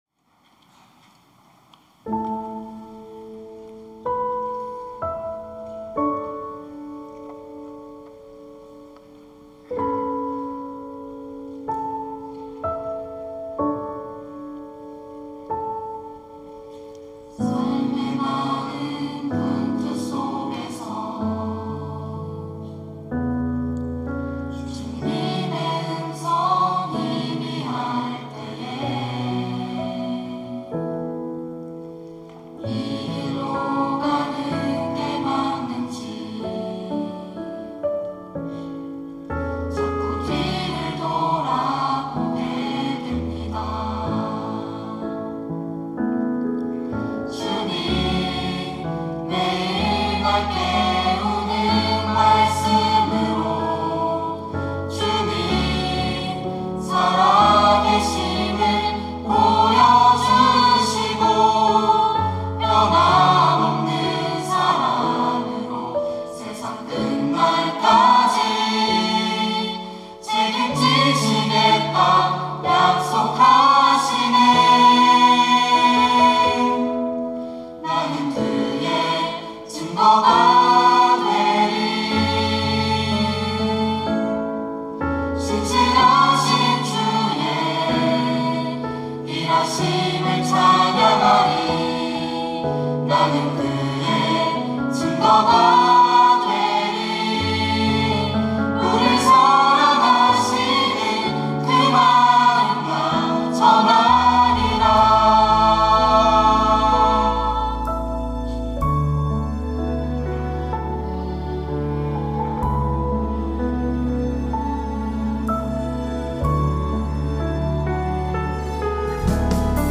특송과 특주 - 나는 그의 증거가 되리